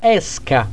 La pronuncia indicata qui è quella standard; va ricordato, però che la pronuncia aperta o chiusa delle e e delle o varia da regione a regione.
é = e chiusa; è e aperta
èsca verb S __ __ __ go out